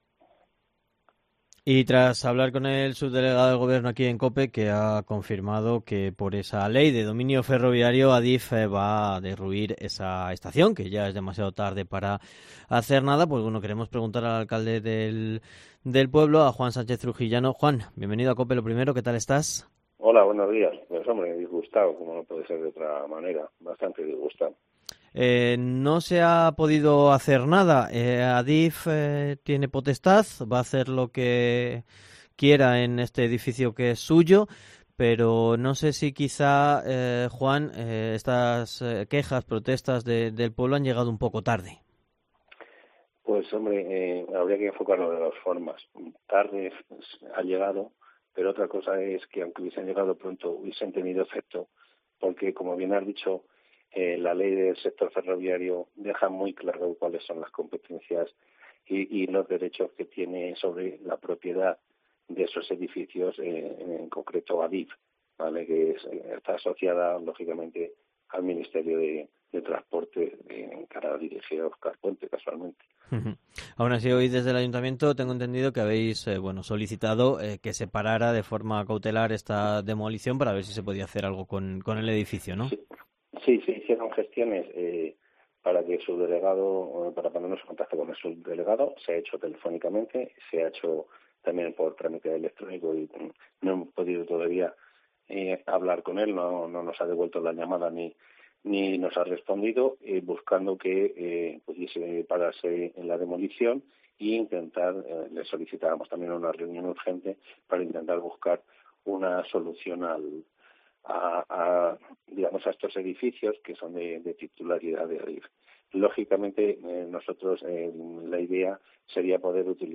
ENTREVISTA / El Alcalde de Mingorria, Juan Sánchez Trujillano en COPE